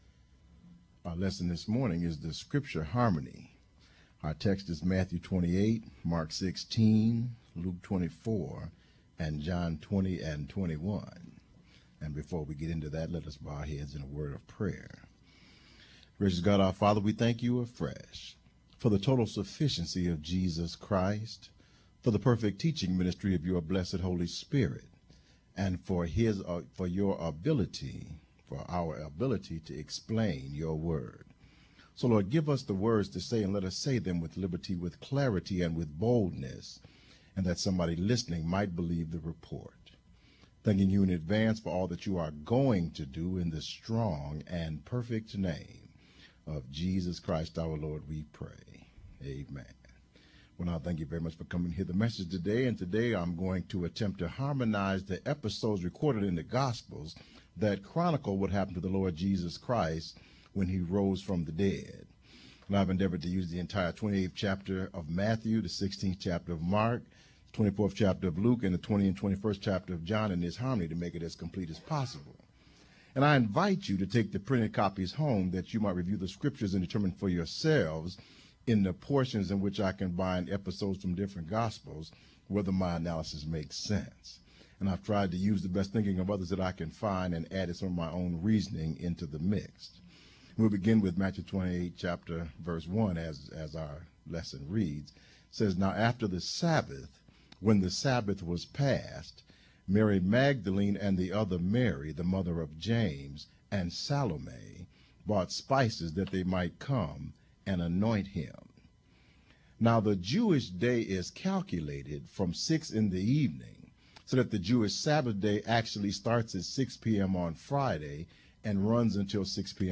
Audio Download: Click to download Audio (mp3) Additional Downloads: Click to download Sermon Text (pdf) Content Feeds Use the links below to subscribe to our regularly produced audio and video content.